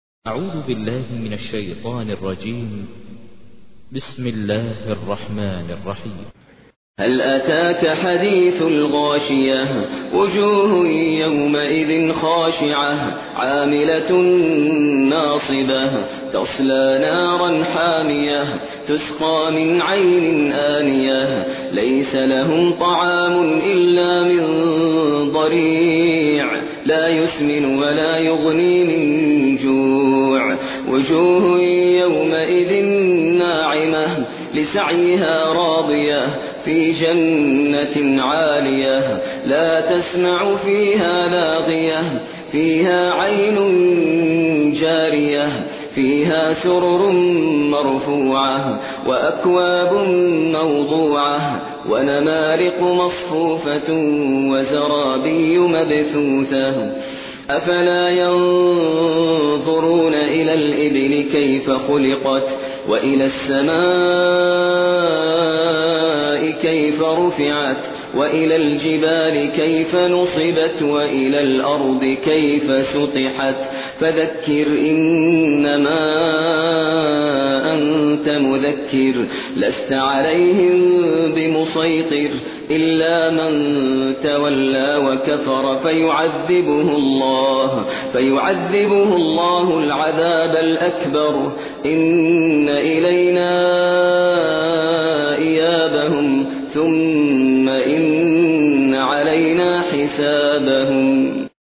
تحميل : 88. سورة الغاشية / القارئ ماهر المعيقلي / القرآن الكريم / موقع يا حسين